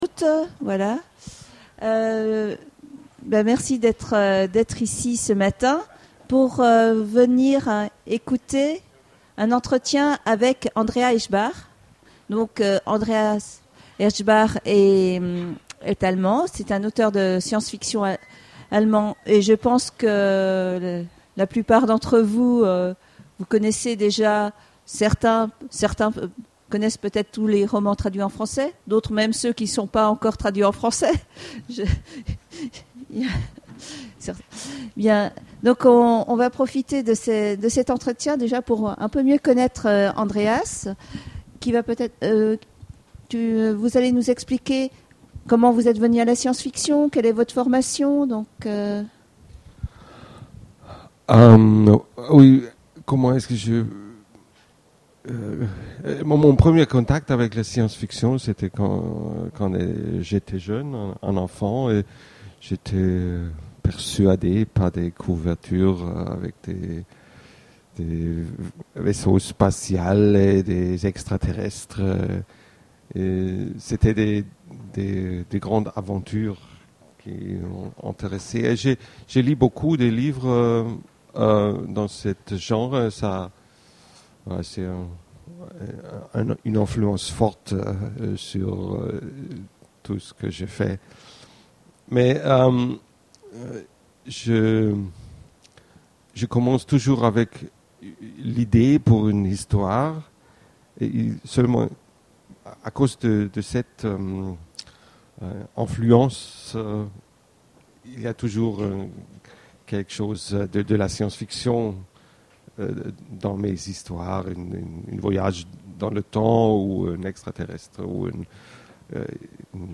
Imaginales 2013 : Conférence Rencontre avec... Andreas Eschbach
Imaginales2013Eschbach.mp3